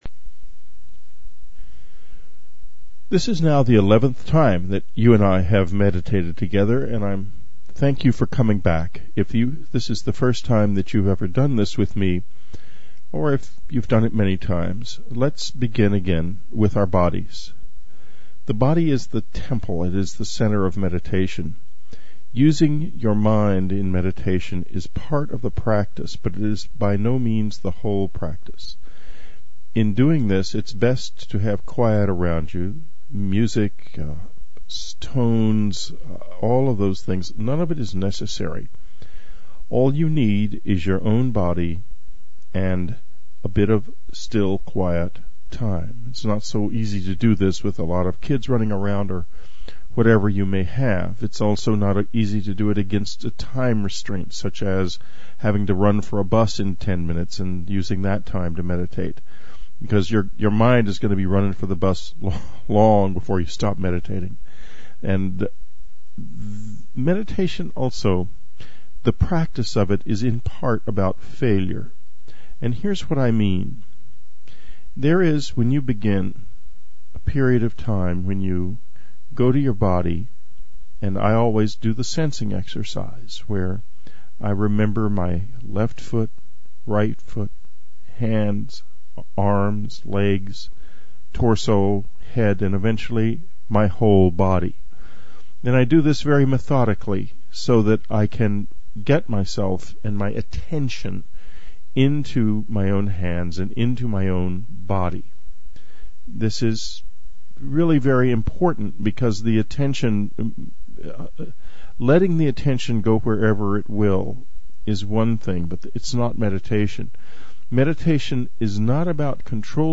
Meditation Series: Eleven
Whitley Strieber offers for our subscribers a “traveling” meditation that uses the energy of the galactic center in the same manner that it was used in ancient times, to accelerate consciousness.